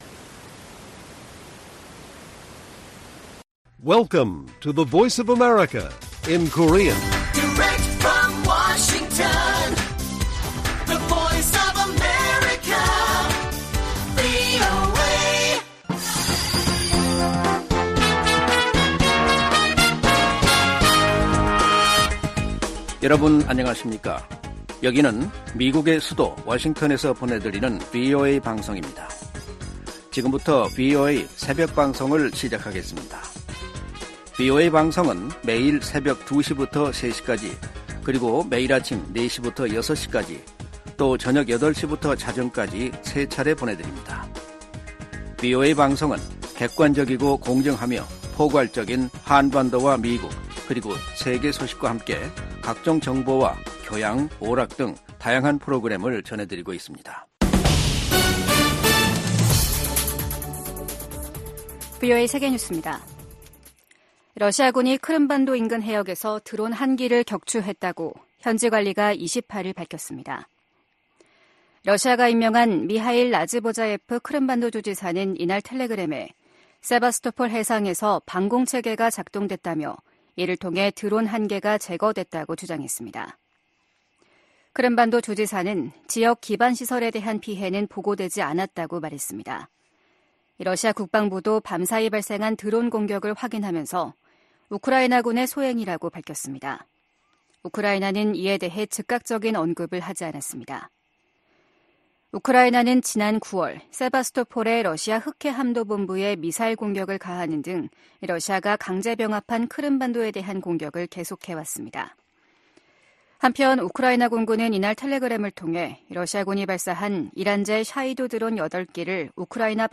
VOA 한국어 '출발 뉴스 쇼', 2023년 12월 29일 방송입니다. 김정은 북한 국무위원장이 당 전원회의서 ‘전쟁 준비에 박차’를 가하는 전투과업을 제시했습니다. 미국 국무부가 북한의 중요 정치행사에 등장한 ‘벤츠 행렬’에 대해 대북 제재의 ‘운송수단 반입 금지’ 의무를 상기시켰습니다. 전 주한미군사령관들이 신년 메시지를 통해 미한 연합훈련과 가치 동맹의 확대를 주문했습니다.